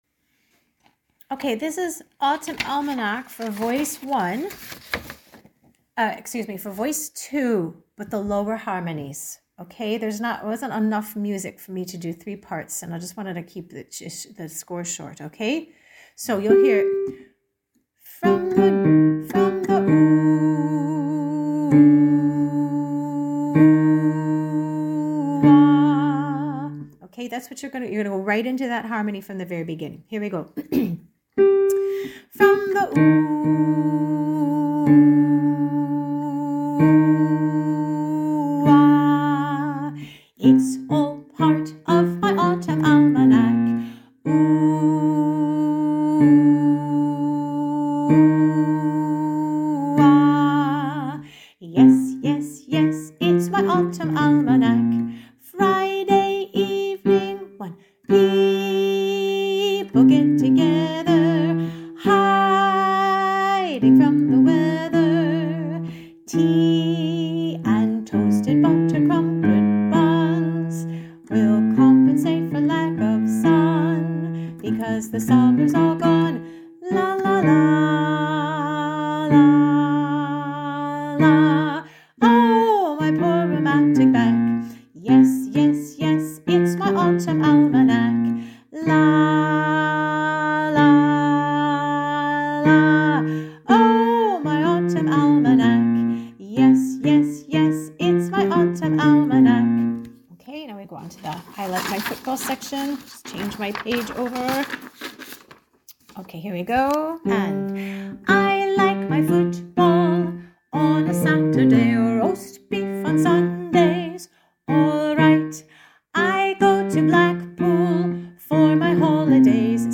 autumn-almanac-voice-2-low.mp3